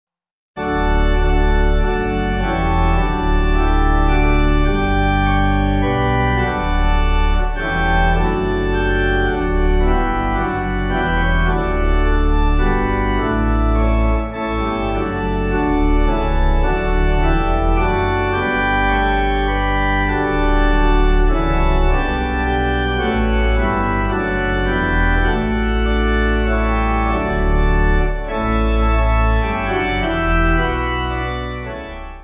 Search by: Calm
Organ